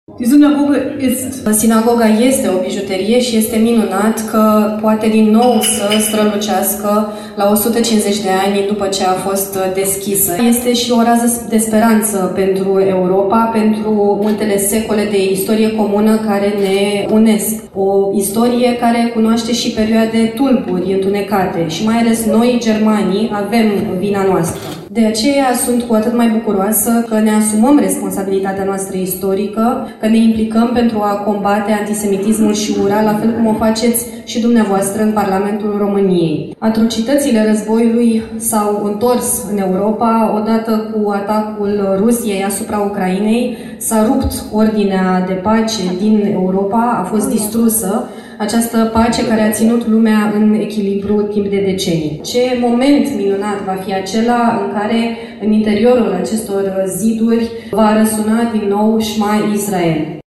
Vicepreședintele Bundestag, Katrin Göring-Eckardt, a vizitat astăzi Timișoara, unde a luat parte la inaugurarea Sinagogii din Cetate.